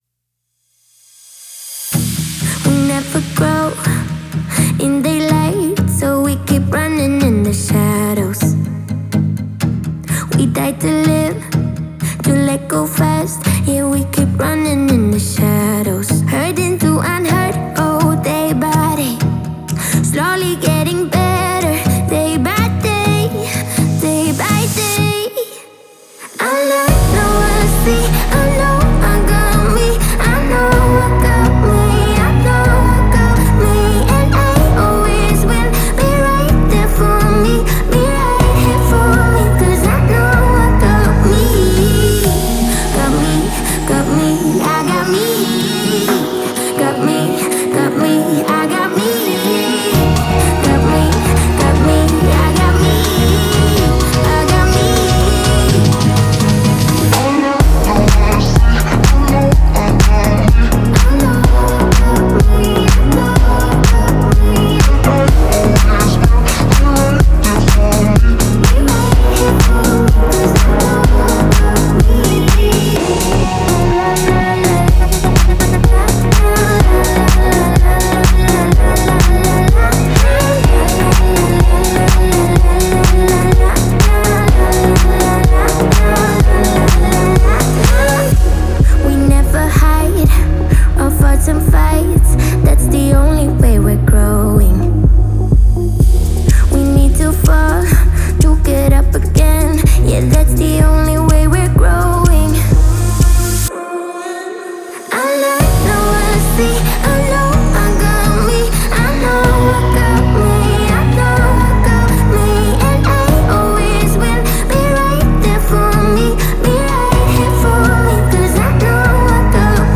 это энергичная трек в жанре хип-хоп и R&B